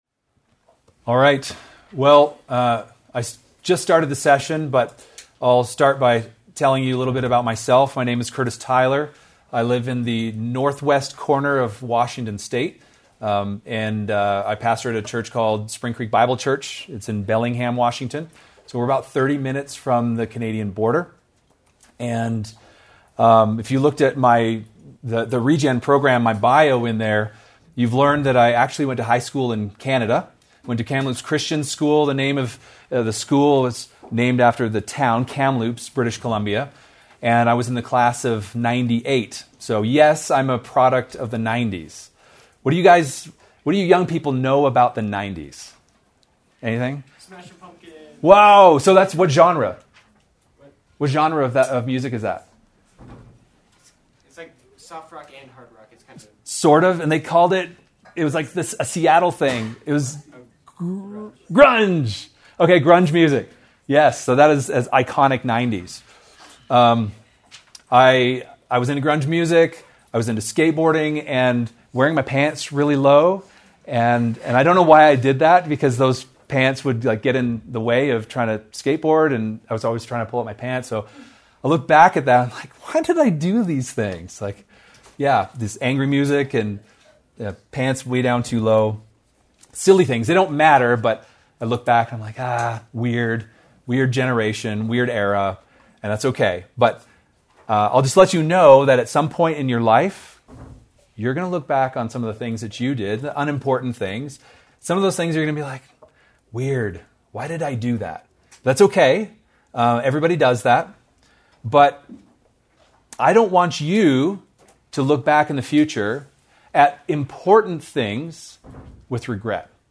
Breakout Session: The Church Needs You and You Need the Church | Camp Regeneration | Grace Community Church
Breakout Session: The Church Needs You and You Need the Church